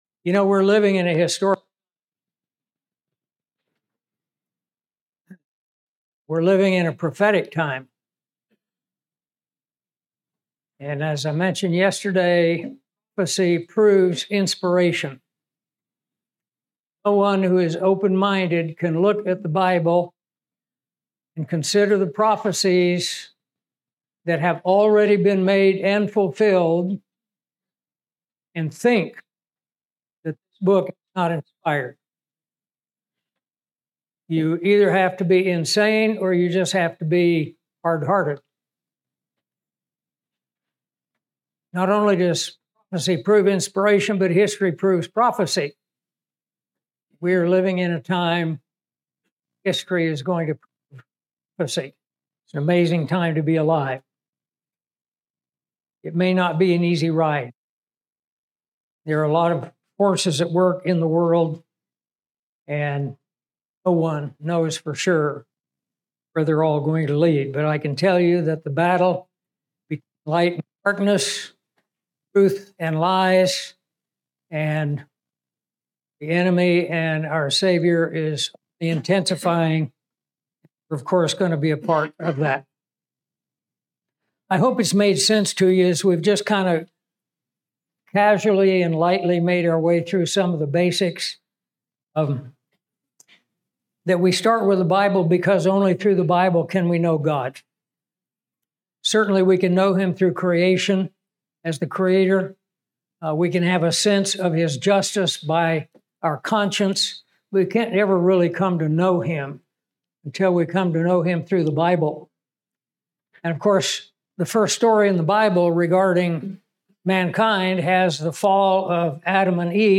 This Bible conference featured seven lesson extracted from The Basics Book.